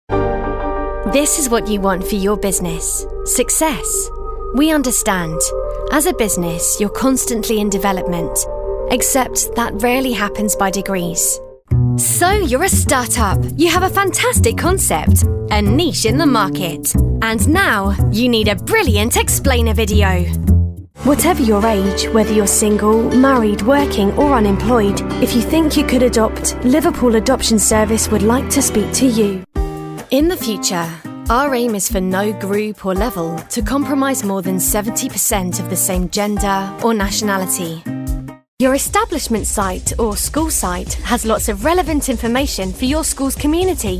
Corporate Showreel
Female
Neutral British
Confident
Friendly